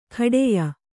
♪ khaḍeya